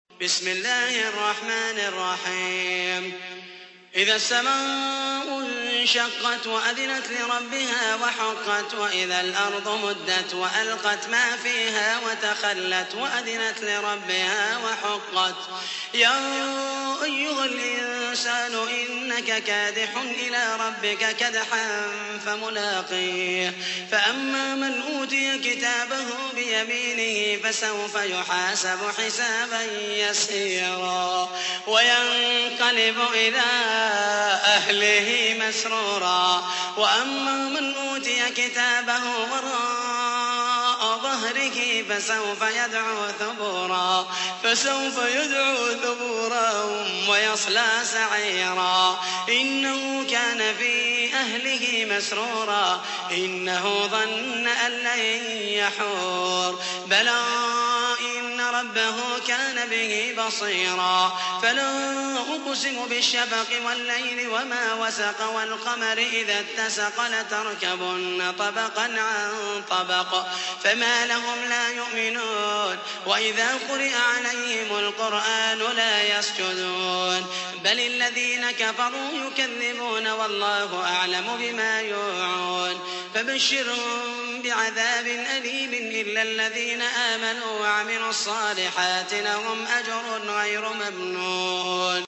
تحميل : 84. سورة الانشقاق / القارئ محمد المحيسني / القرآن الكريم / موقع يا حسين